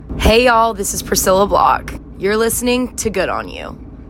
LINER Priscilla Block (Good On You) 4